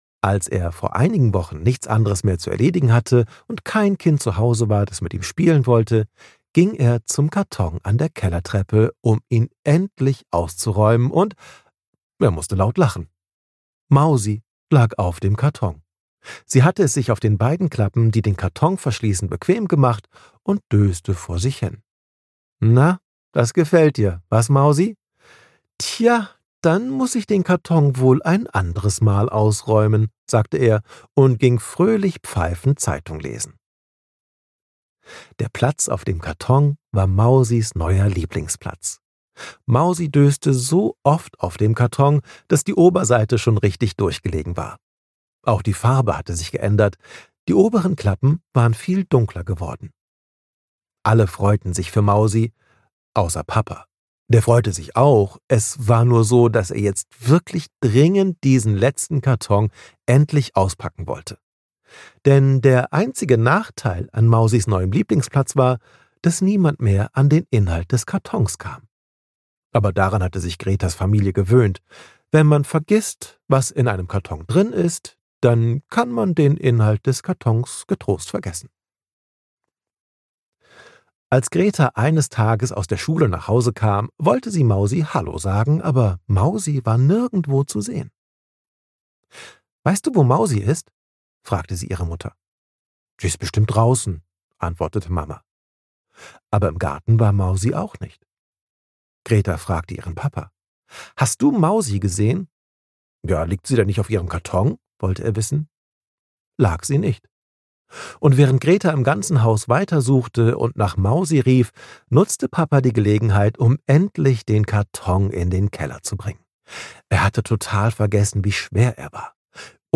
Wenn Glühwürmchen morsen - Ralph Caspers - Hörbuch